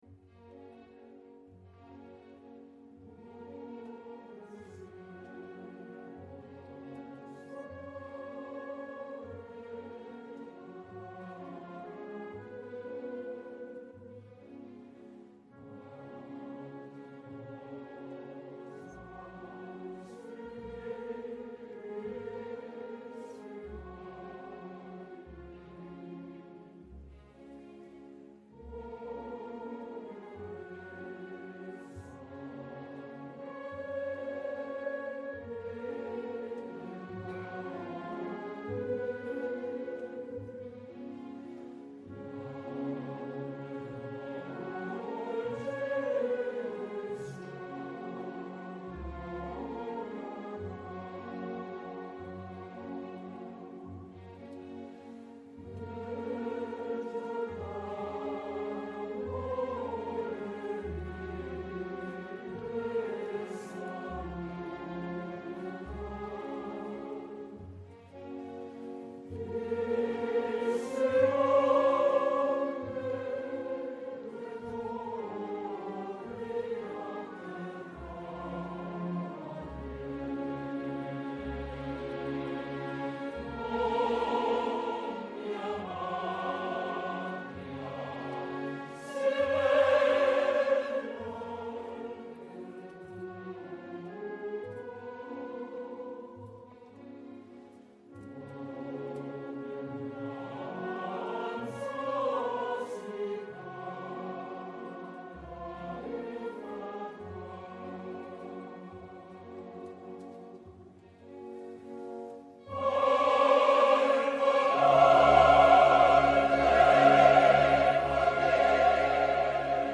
Στην σημερινή εκπομπή καλεσμένοι :
-O Παντελής Καψής, Δημοσιογράφος